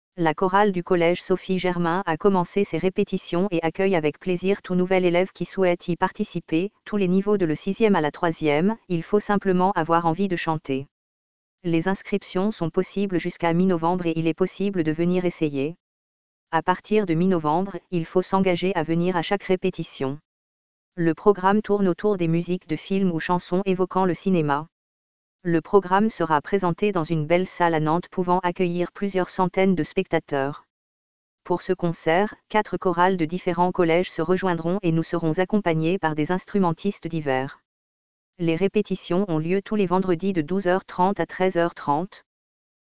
Chorale du collège
Chorale.mp3